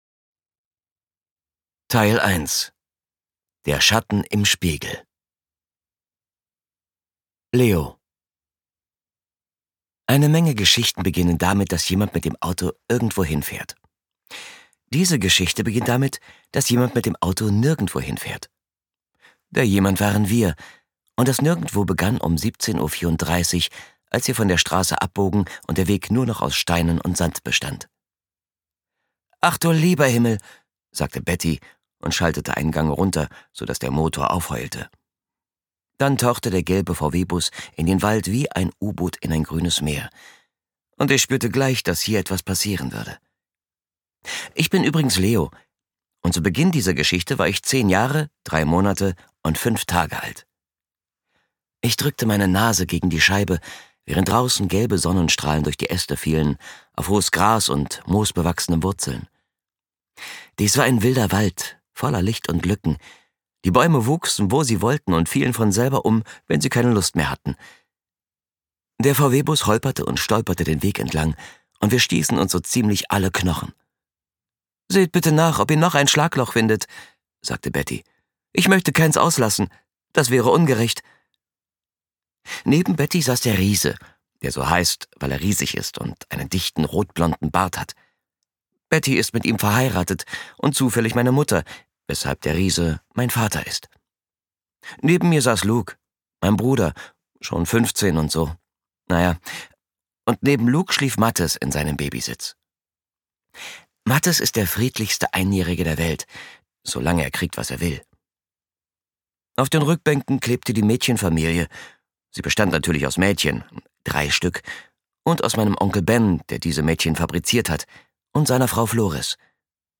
ungekürzte Lesung
Ob Thriller oder Kinderbuch: Mit ihrer kristallklaren, ausdrucksstarken Stimme nimmt sie die Hörer mit in die verschiedensten Roman-Landschaften.